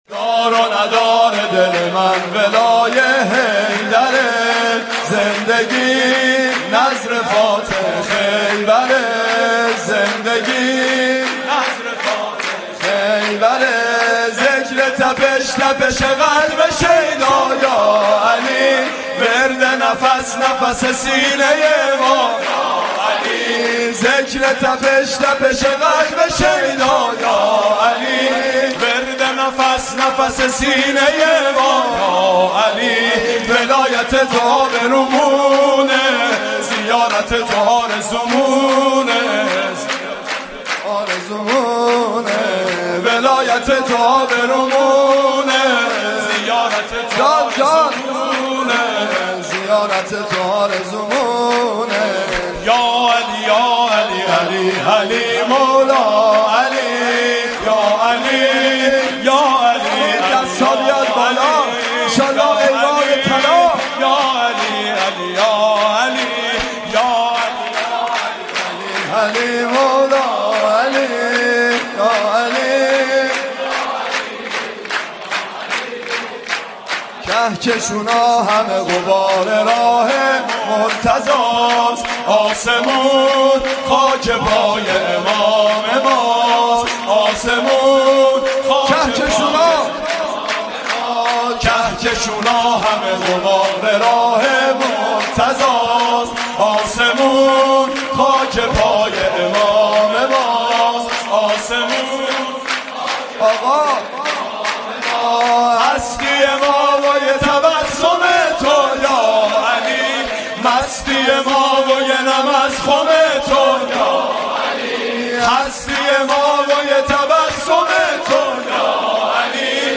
برچسب ها: مولودی ، جشن ، عید غدیر ، ایکنا